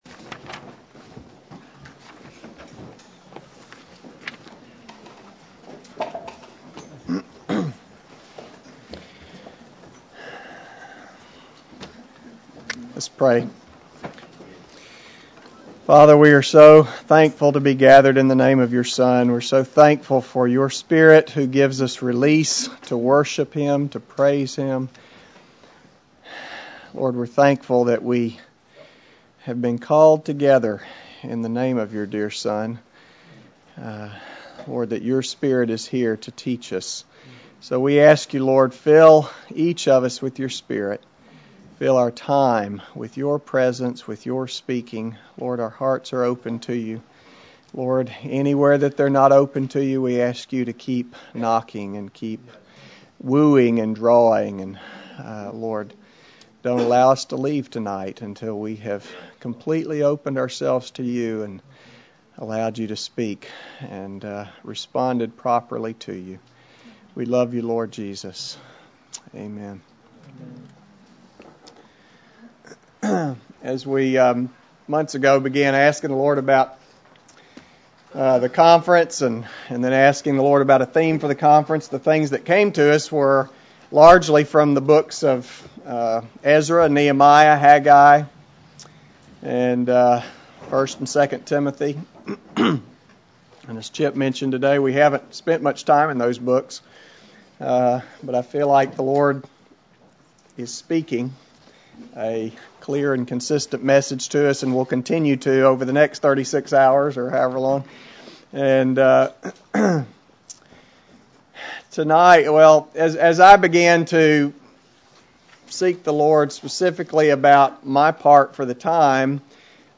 A collection of Christ focused messages published by the Christian Testimony Ministry in Richmond, VA.
2009 Memphis Conference: A Call To Build